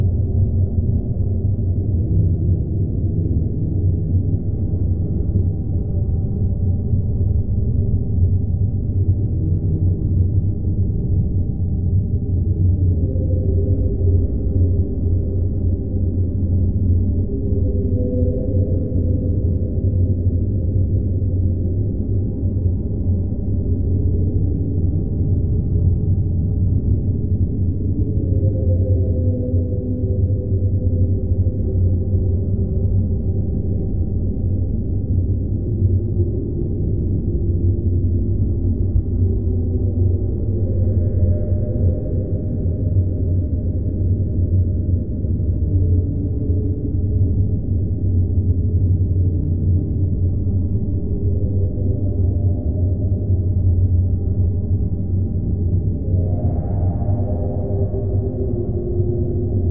creepycave_d.wav